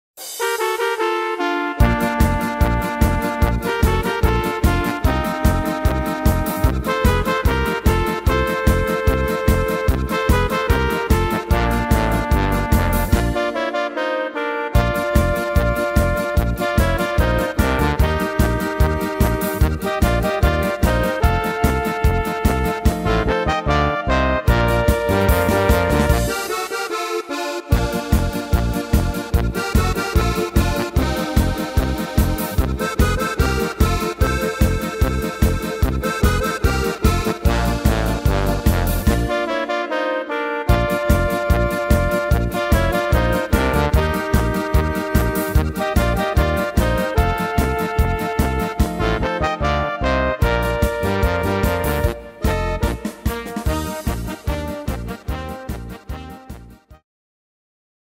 Tempo: 148 / Tonart: Bb-Dur